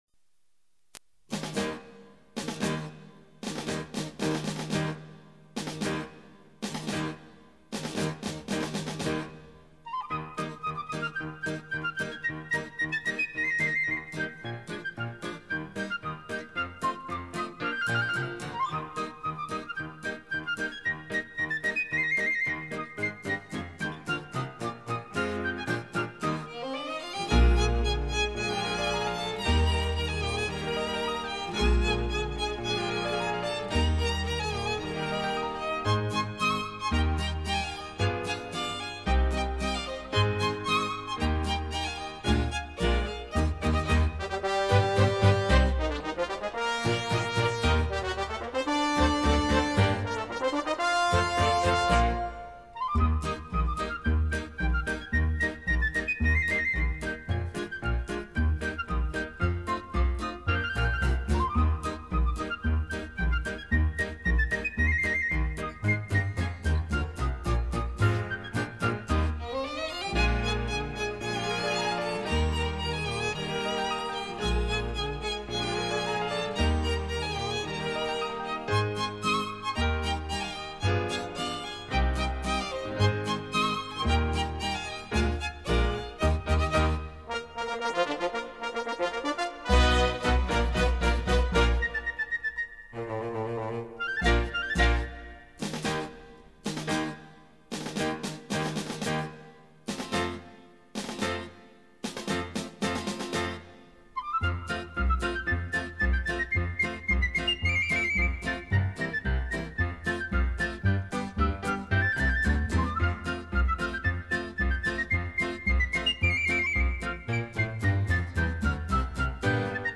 ***唱片 资源：CD抓轨320K/MP3 CAV的试音碟是绝对值得收藏的，听了之后，感觉音质超棒，相信你不会后悔的。